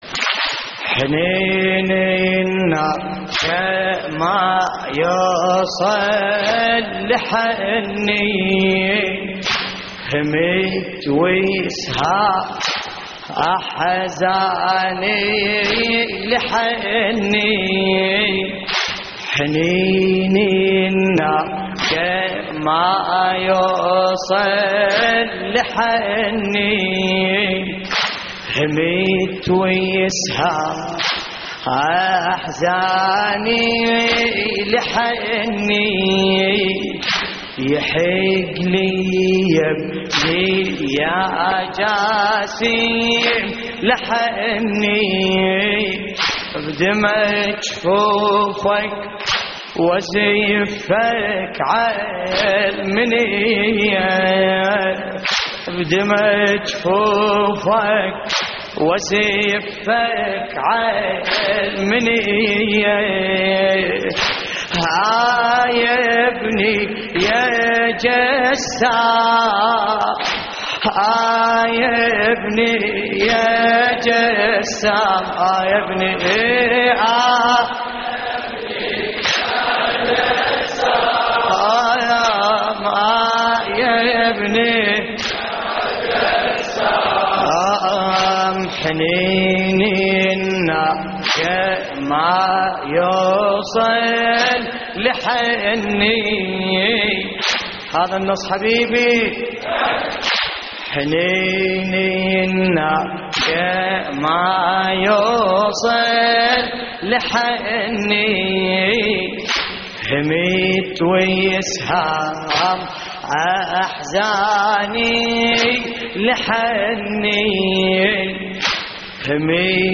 تحميل : حنين الناقة ما يوصل لحني/آه يا ابني يا جسام / الرادود باسم الكربلائي / اللطميات الحسينية / موقع يا حسين